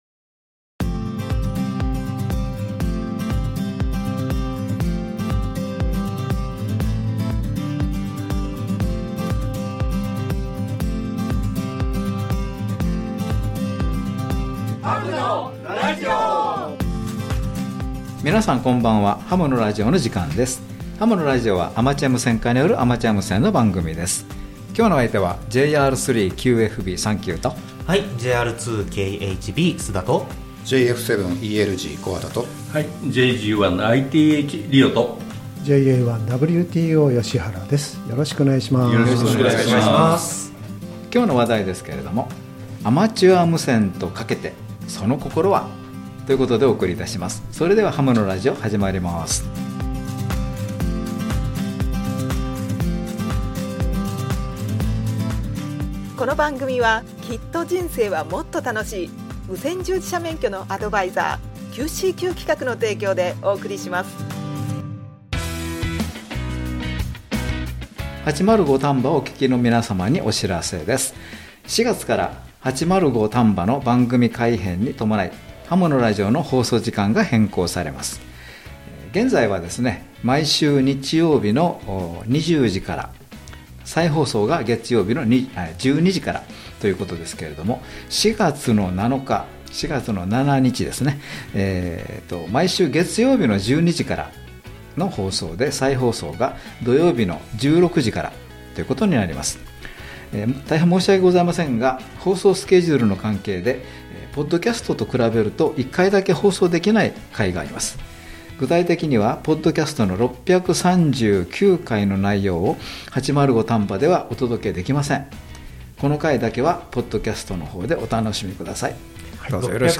アマチュア無線家によるアマチュア無線のラジオ番組